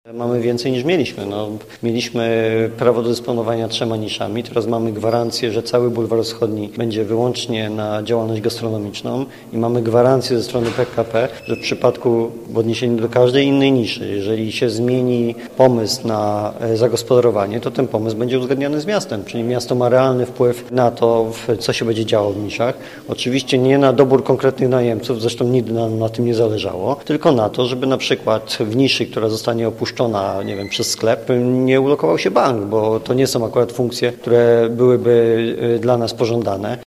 – Porozumienie jest efektem trudnych i długich negocjacji prowadzonych z PKP PLK – mówi wiceprezydent Jacek Szymankiewicz: